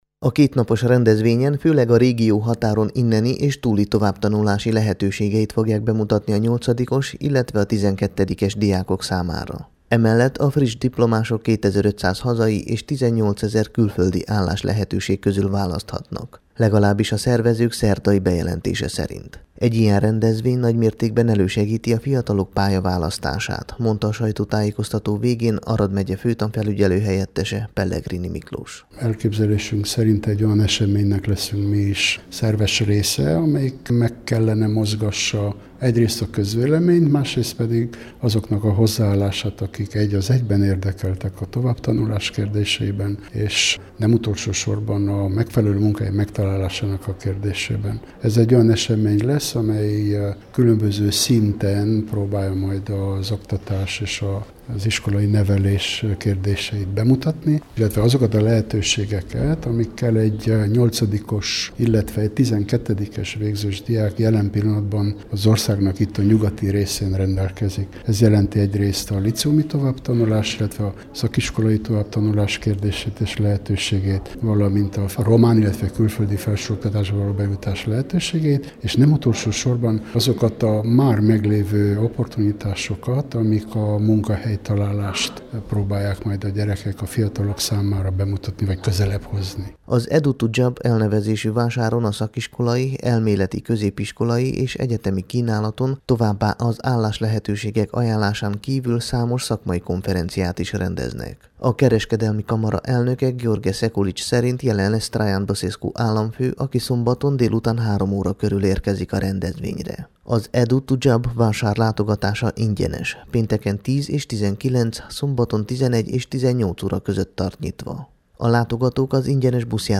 tudósítását, amely a Temesvári Rádió számára készült